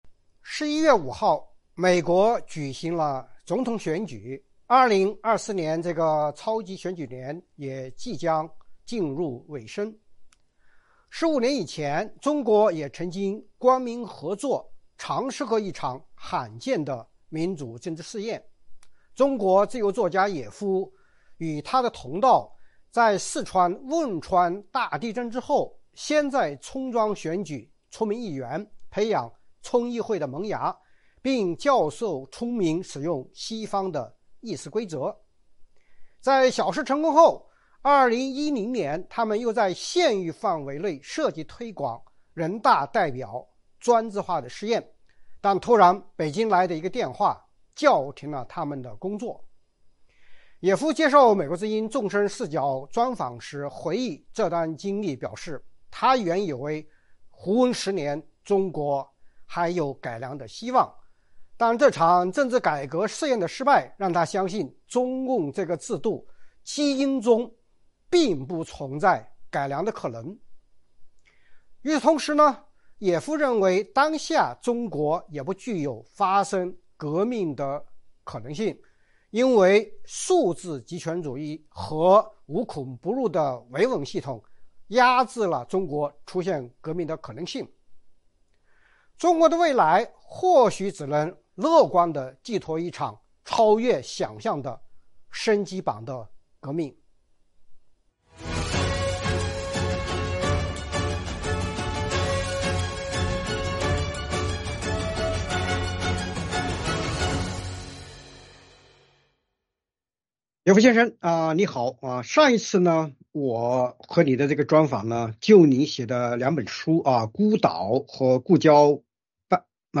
专访野夫：一场低调政改实验的夭折和中国改良梦的终结
《纵深视角》节目进行一系列人物专访，受访者发表的评论不代表美国之音的立场 。